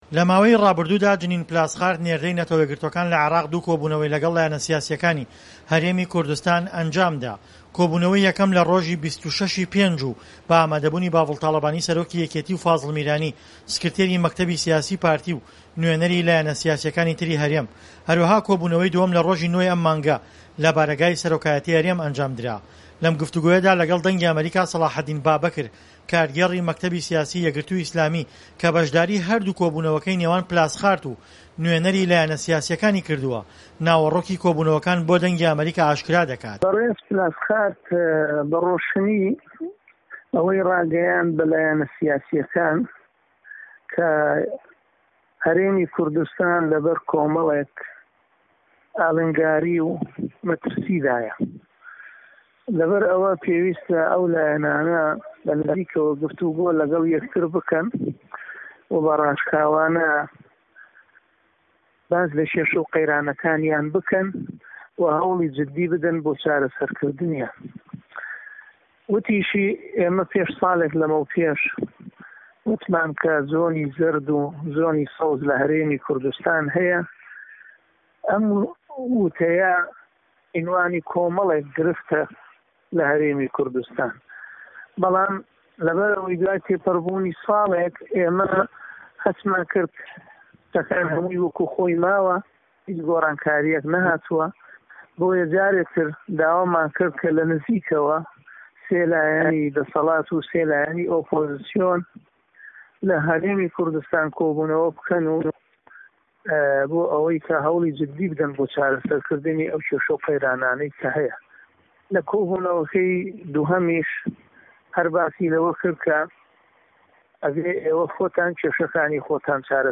لەم گفتووگۆیەدا لەگەڵ دەنگی ئەمەریکا